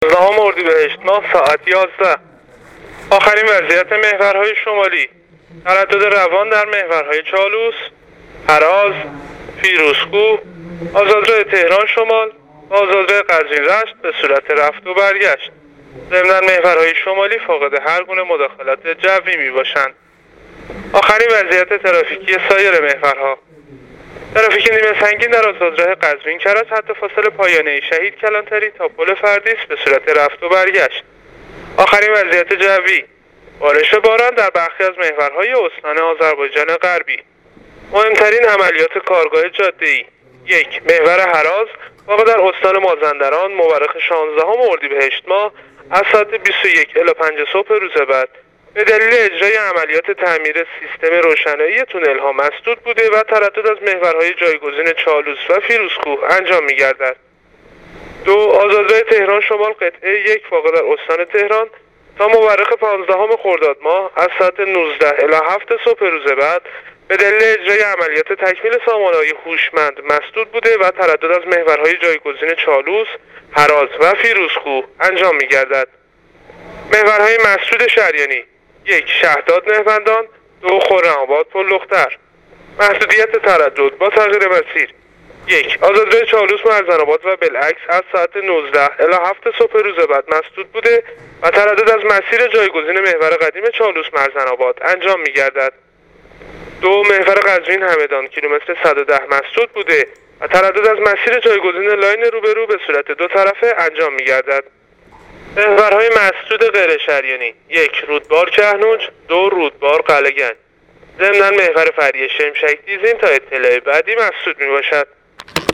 گزارش رادیو اینترنتی از آخرین وضعیت ترافیکی جاده‌ها تا ساعت ۱۱ شانزدهم اردیبهشت ۱۳۹۹